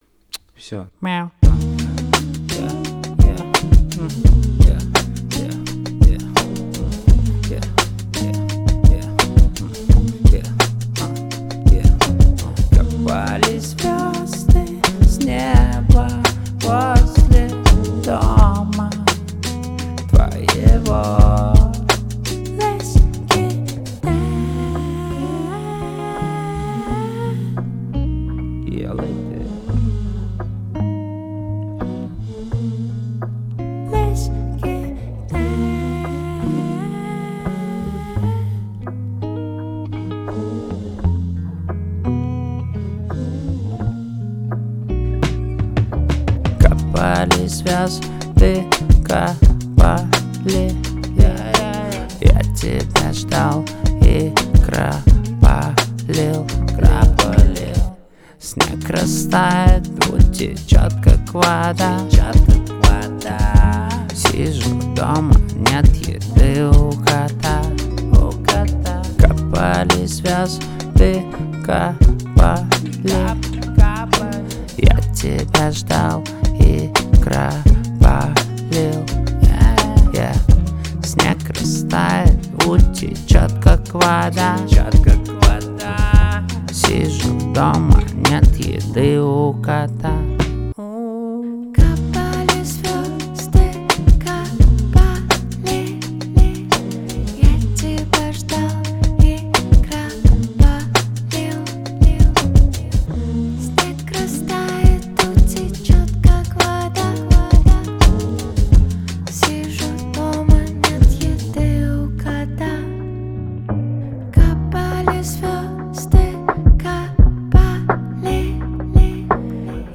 8.82 Mb Джаз Качество